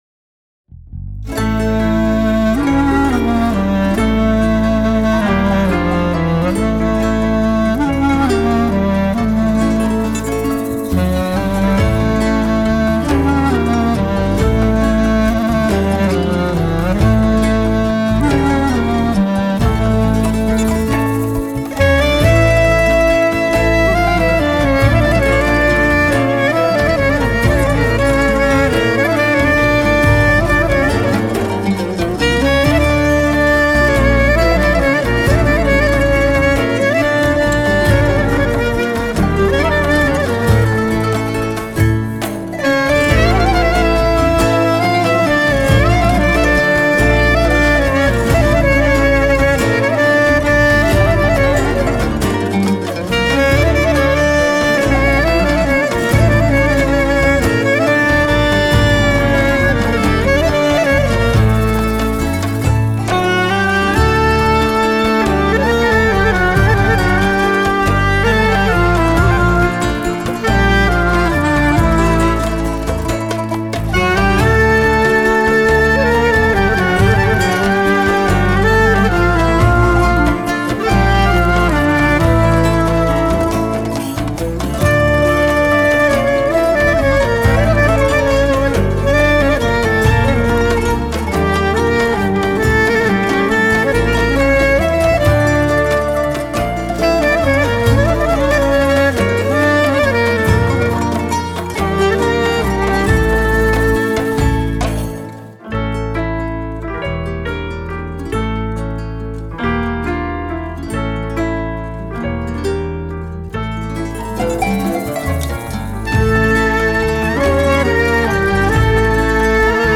با همراهیِ ارکستری از کشور ترکیه
کلارینت